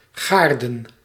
Ääntäminen
Ääntäminen France: IPA: [ʒaʁ.dɛ̃] Haettu sana löytyi näillä lähdekielillä: ranska Käännös Ääninäyte Substantiivit 1. tuin {m} 2. hof {n} 3. gaard 4. gaarde {f} 5. gaarden Muut/tuntemattomat 6. erf {n} Suku: m .